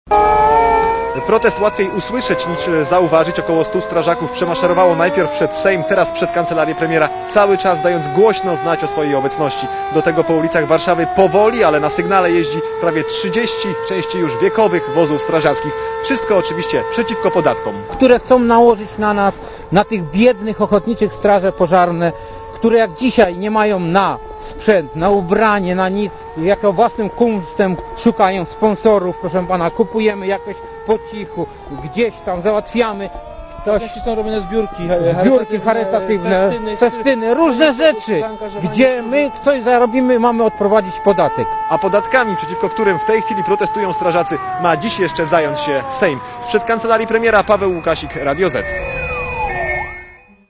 Posłuchaj relacji reportera Radia Zet (200Kb)